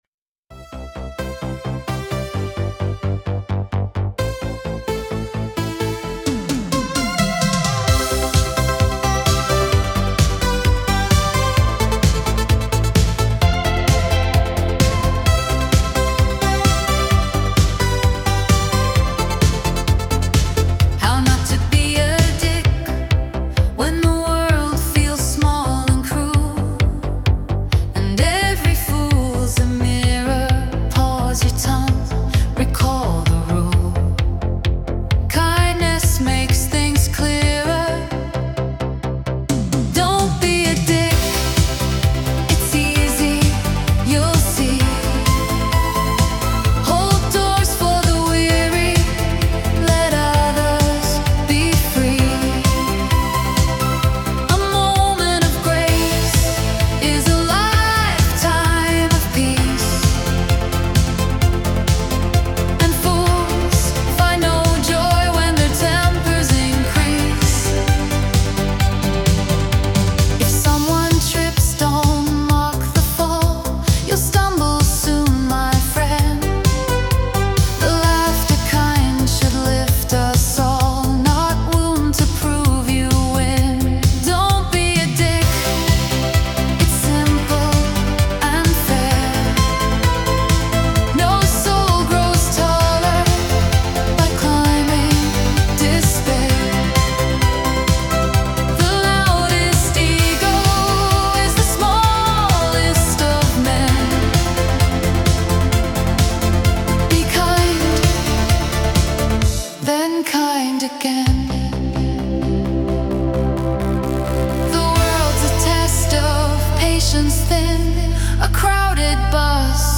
Electronic / EDM